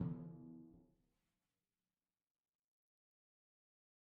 timpani4-hit-v2-rr1-sum.mp3